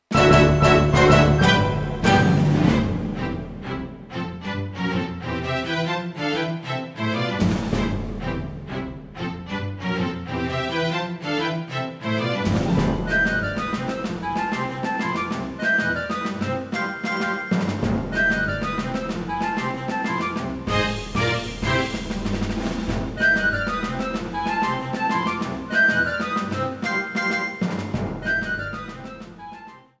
Trimmed and added fadeout